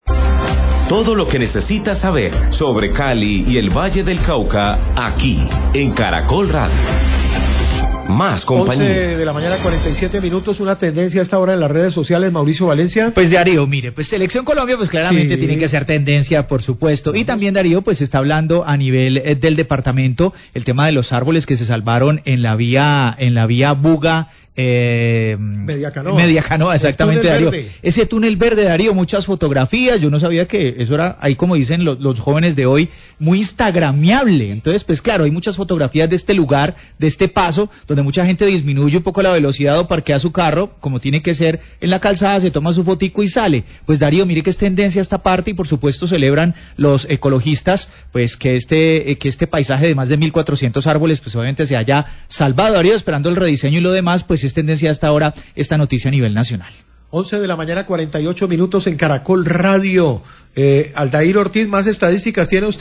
Radio
Comenta el periodista que la negativa de CVC a otorgar el permiso ambiental para el aprovechamiento forestal a la concesión que adelanta la doble calzada Buga-Buenaventura, se convirtió en tendencia en redes.